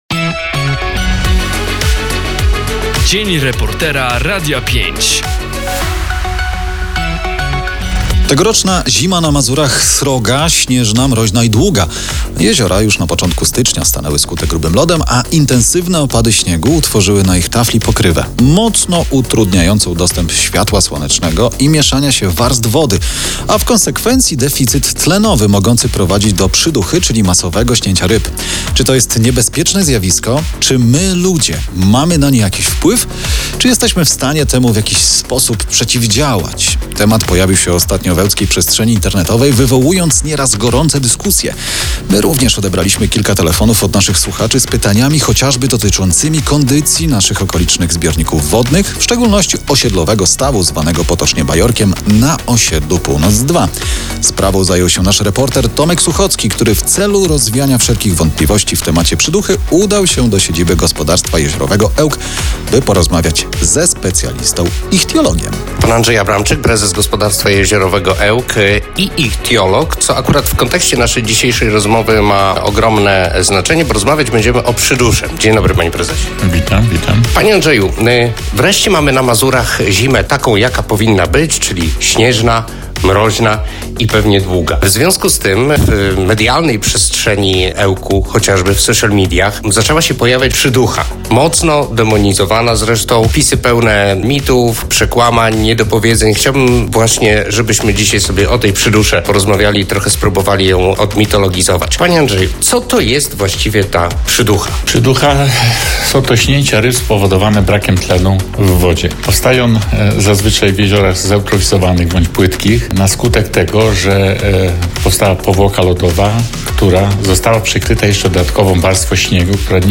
by porozmawiać ze specjalistą – ichtiologiem.
12.02-dzien-reportera-na-emisje-z-jinglami.mp3